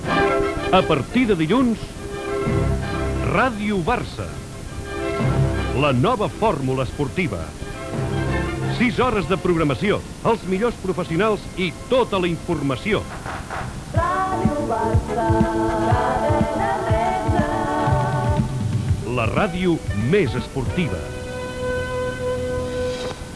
Promoció i indicatiu cantat
FM